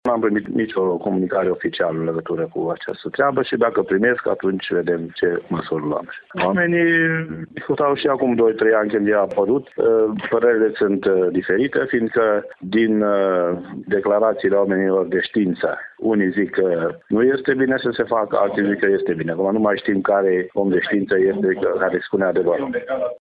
Primarul comunei Sintea Mare, de care aparţine perimetrul Adea, Valentin Erdos, spune că deocamdată nu a primit niciun comunicat oficial:
primar-sintea.mp3